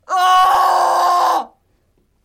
电影尖叫声 " 尖叫 01
描述：一个样本恐怖尖叫的声音。
标签： 尖叫 尖叫 恐惧 呼喊 恐怖 痛苦 666moviescreams 疼痛 男孩
声道立体声